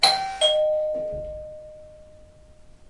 叮咚 " 叮咚4
描述：一个简单的门铃